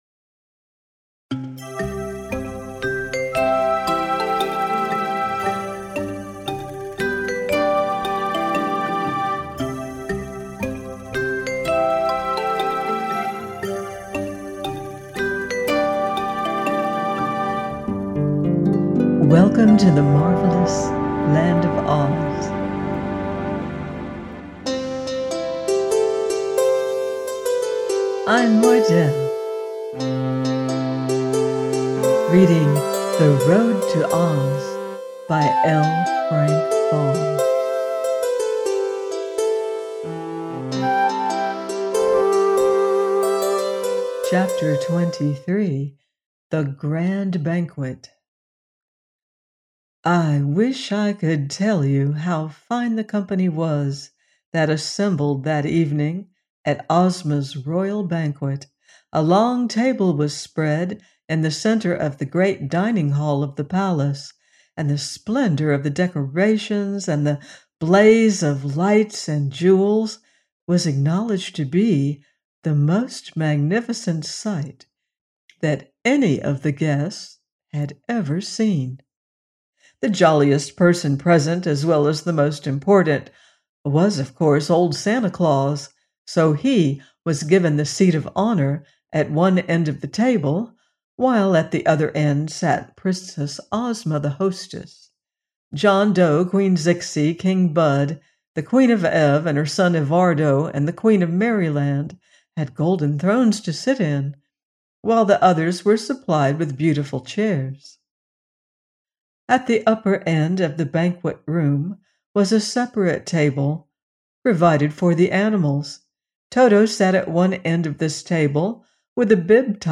The Road To OZ – by L. Frank Baum - audiobook